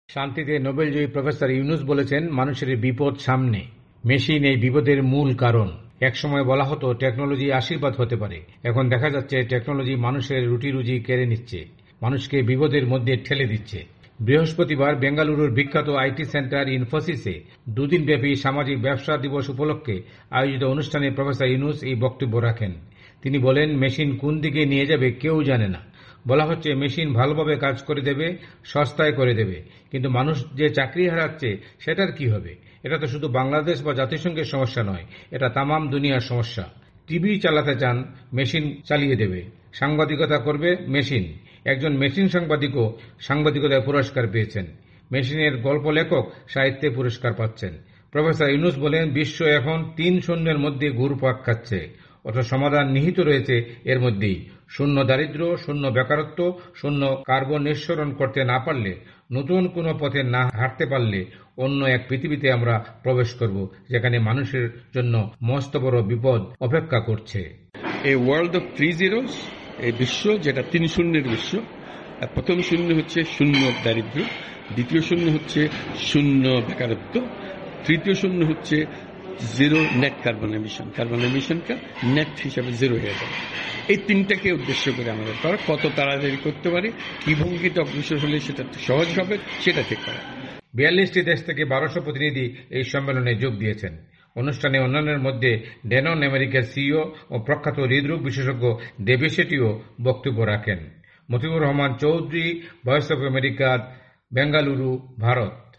বৃহস্পতিবার ব্যাঙ্গালুরুর বিখ্যাত আইটি সেন্টার ইনফোসিস-এ সামাজিক ব্যবসা দিবস উপলক্ষে দুই দিনব্যাপী আয়োজিত অনুষ্ঠানে প্রফেসর ড. ইউনূস এ সতর্কবার্তা উচ্চারণ করেন।